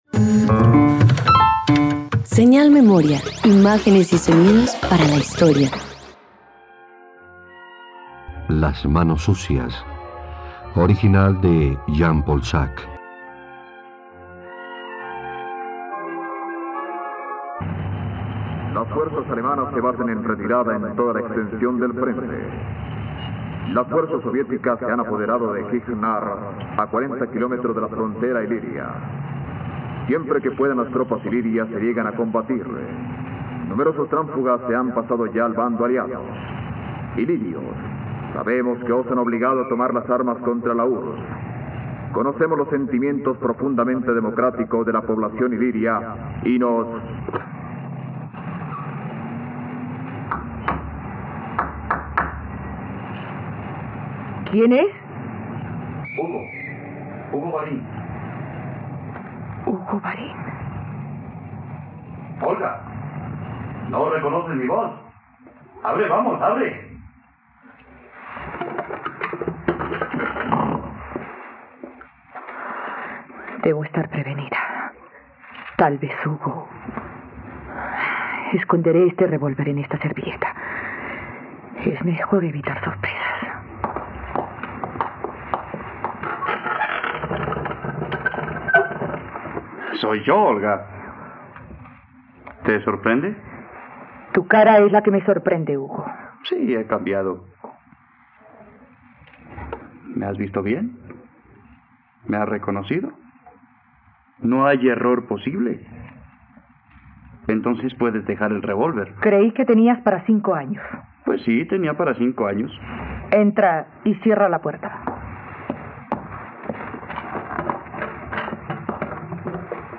Las manos sucias - Radioteatro dominical | RTVCPlay